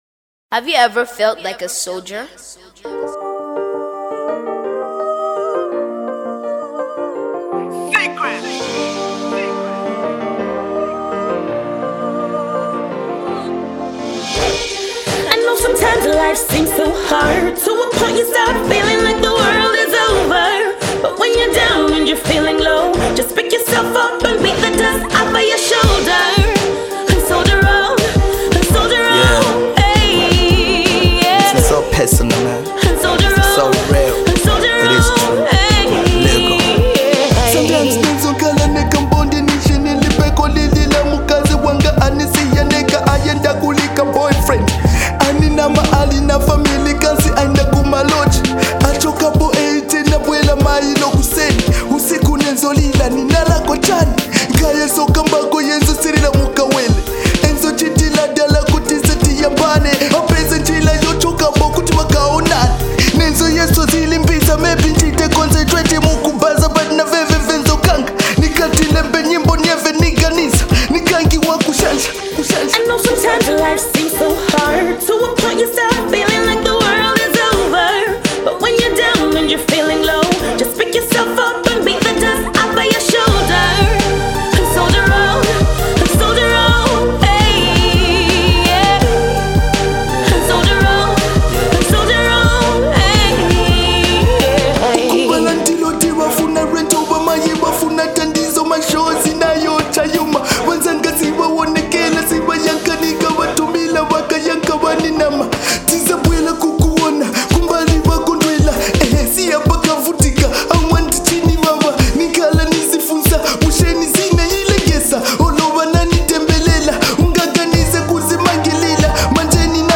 uplifting beat